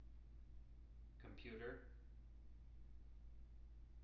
wake-word
tng-computer-345.wav